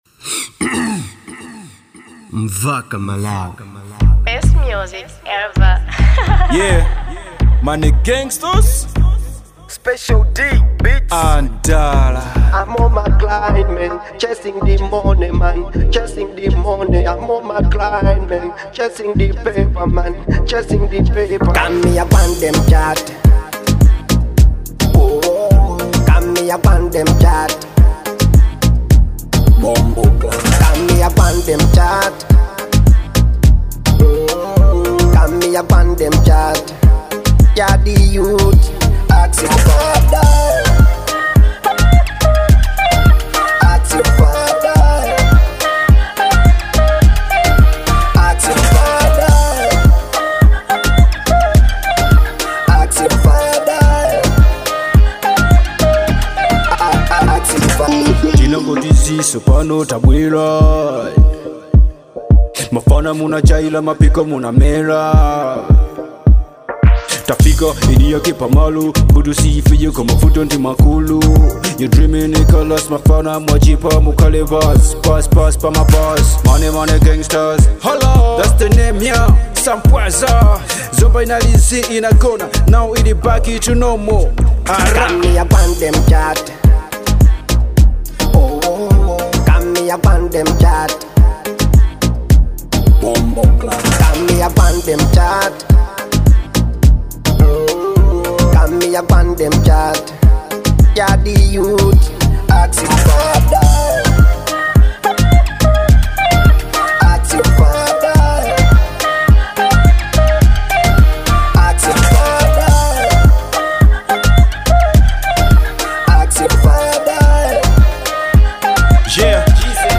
type:Dancehall